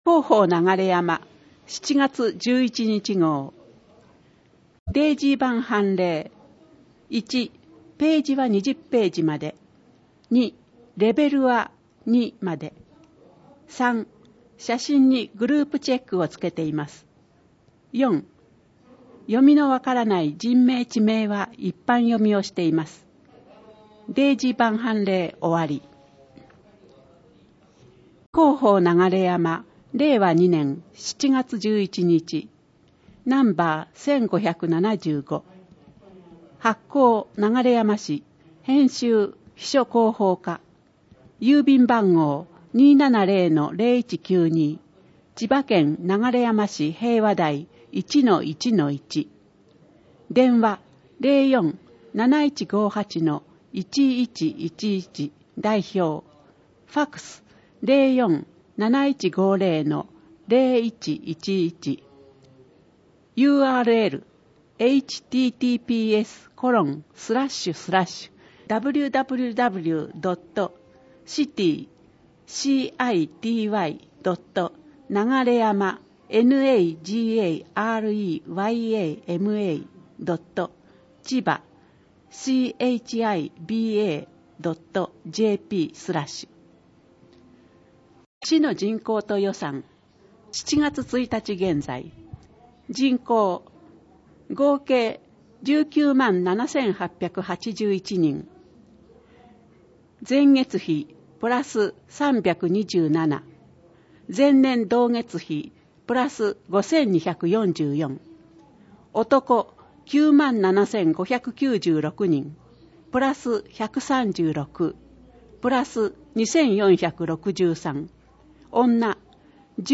音訳情報